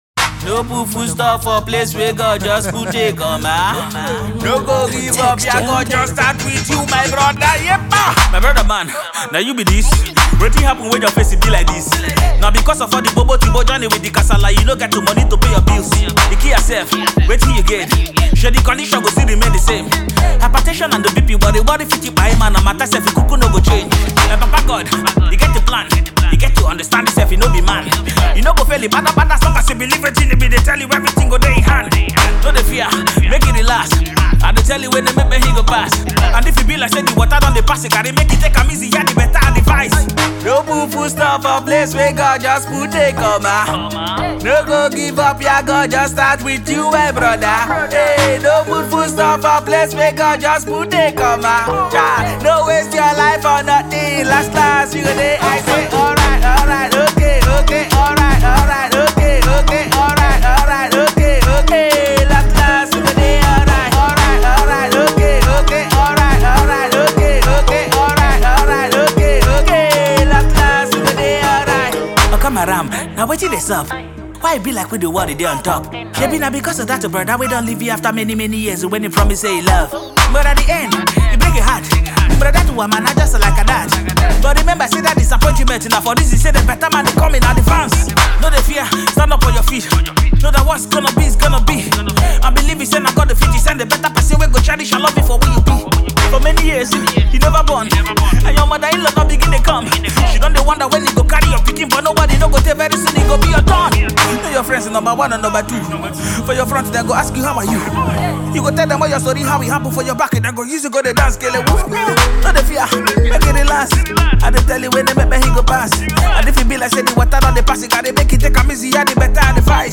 Nigerian rapper, songwriter and record producer